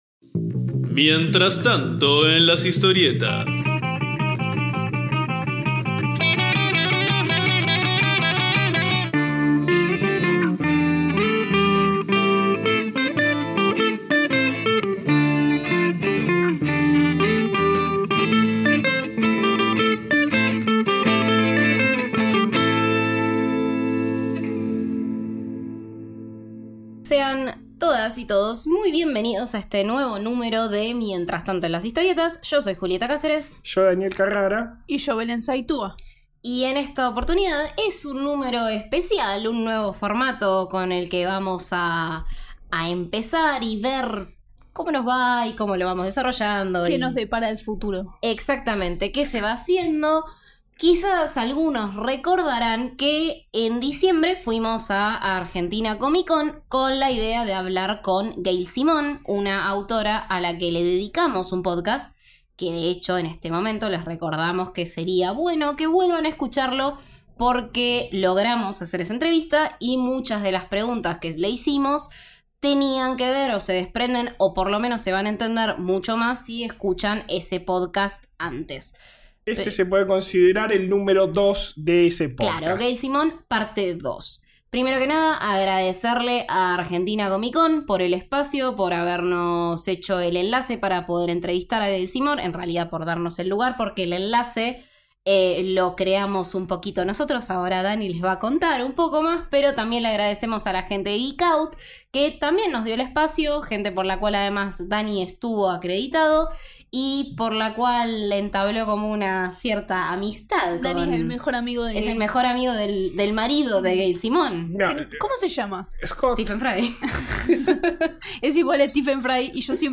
Entrevista a Gail Simone
En diciembre Gail Simone estuvo en la Argentina Comic-Con... y nosotros también. En este número especial, entrevistamos a la autora y le preguntamos sobre los personajes que ama, sus inolvidables historias, la forma en que trabaja y mucho más.